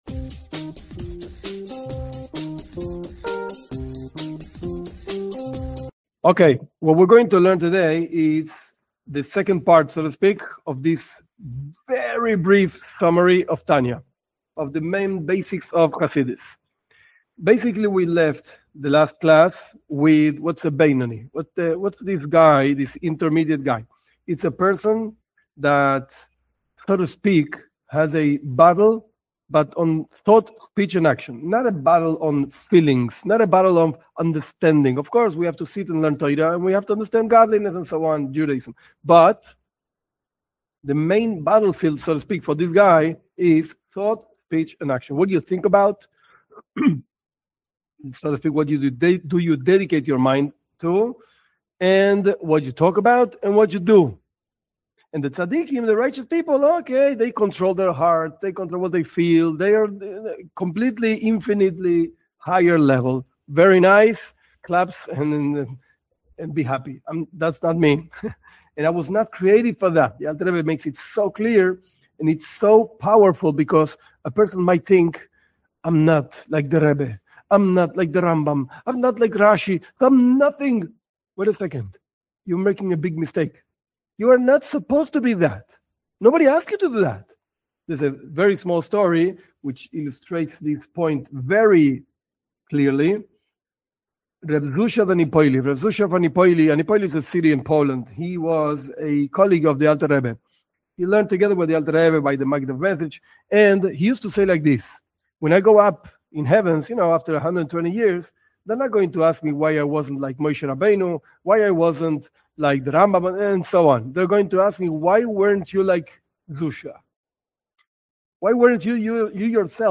This class is the second of a series where the most basic book of Chabad Chassidism is summarized. The most basic themes and ideas of the Jewish perspective on serving God.